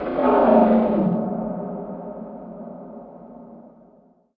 PixelPerfectionCE/assets/minecraft/sounds/mob/enderdragon/growl4.ogg at 7a61fa0703aaddfec28ba8995ee82bf7a5fd0bca
growl4.ogg